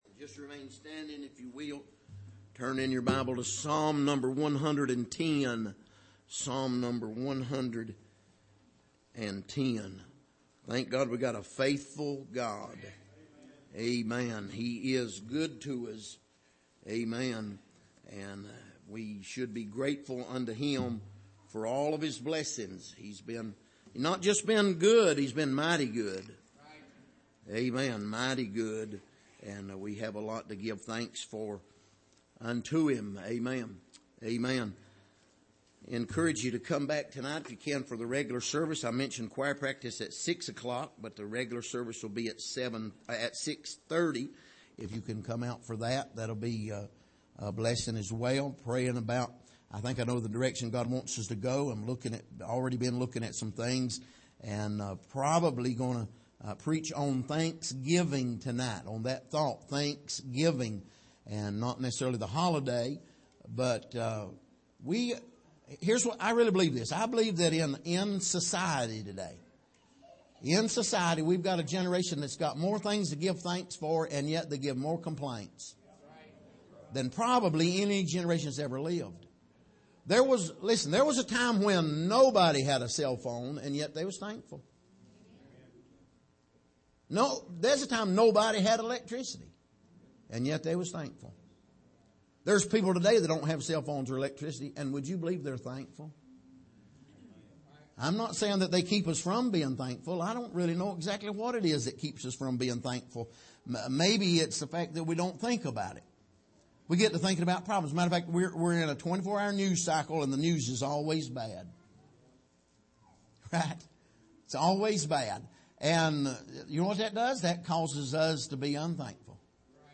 Passage: Psalm 110:1-7 Service: Sunday Morning